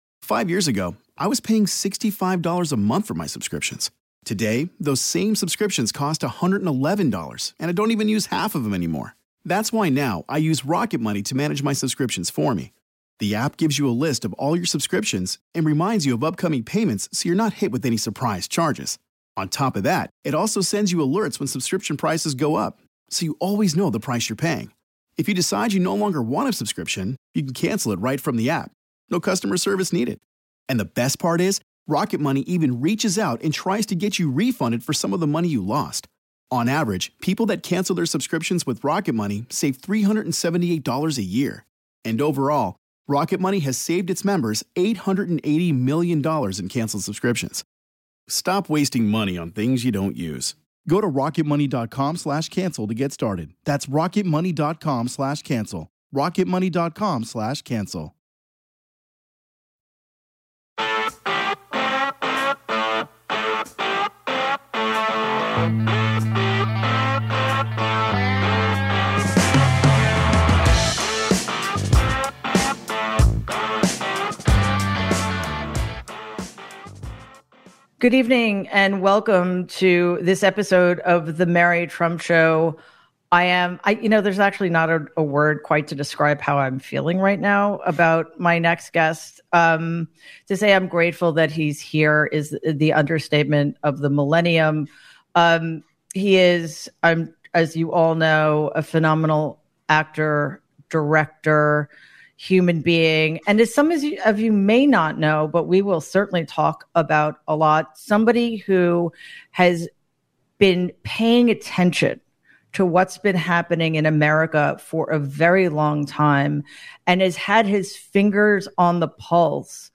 With the country tuning into the 1/6 committee hearings, Mary Trump is joined by filmmaker, actor, producer, and activist Rob Reiner to look at the threats facing our system. In their conversation they discuss the forces that abetted Donald’s rise, from the mainstream media to a complicit Republican party, and the ways we can retake our democracy while finding a winning message.